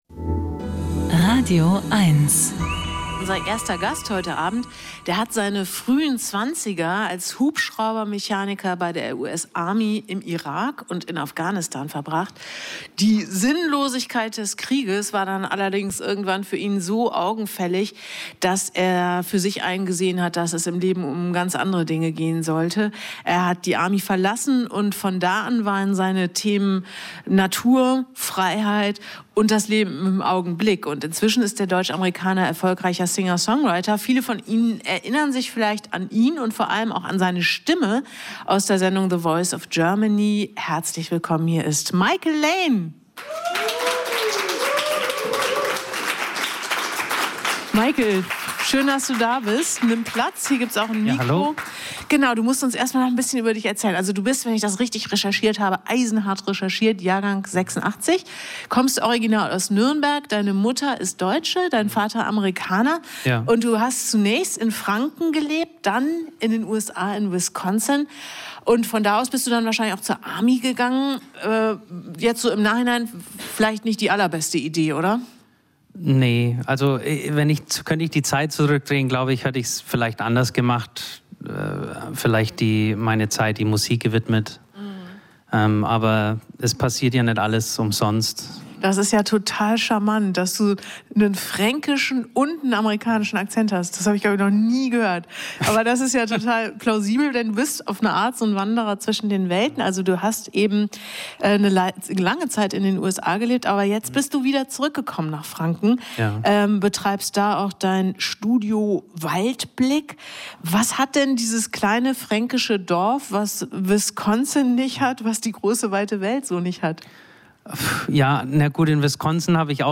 Musik-Interviews Podcast